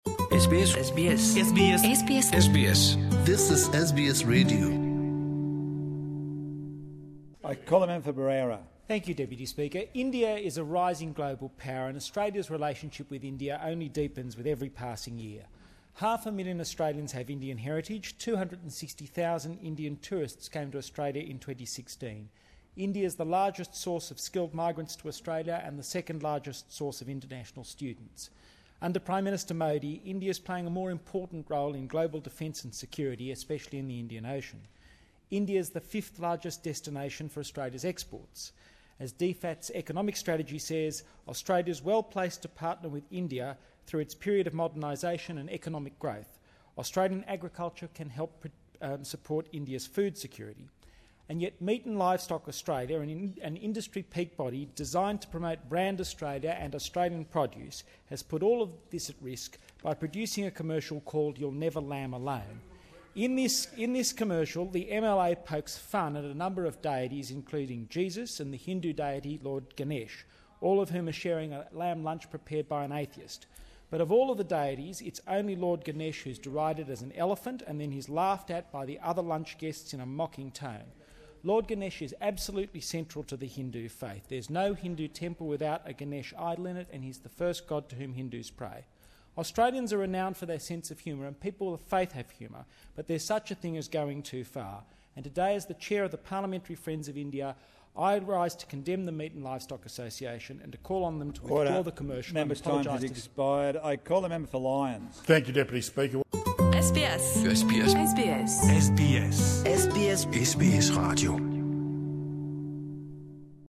MP Julian Leeser condemns meat ad featuring Lord Ganesh in parliament